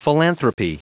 Prononciation du mot philanthropy en anglais (fichier audio)